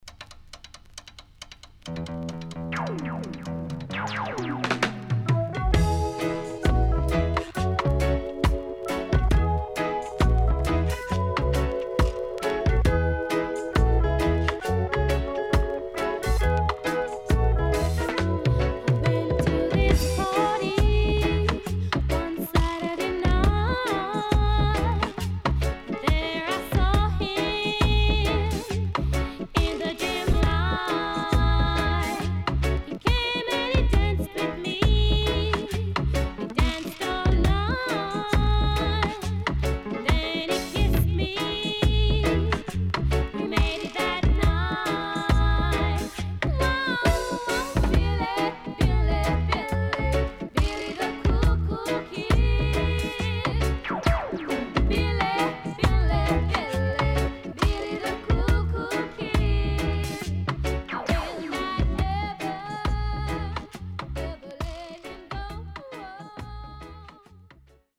SIDE AA:少しチリノイズ入りますが良好です。